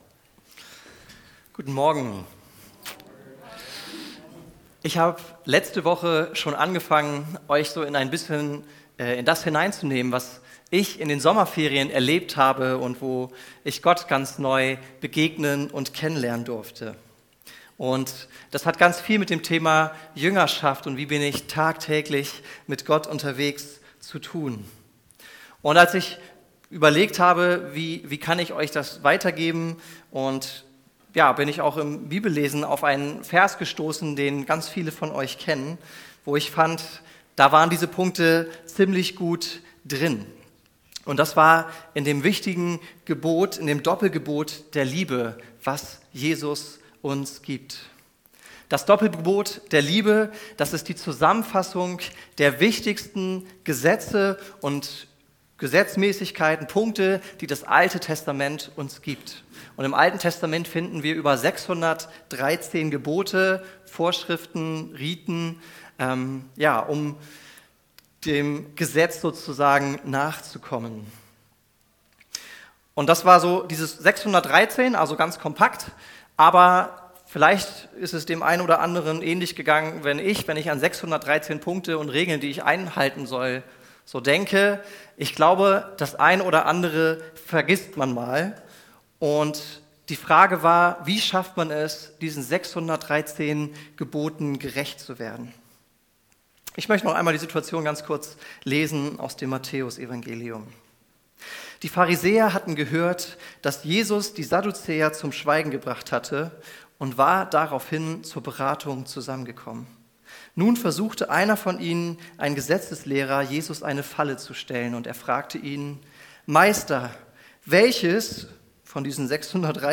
das größte Gebot Prediger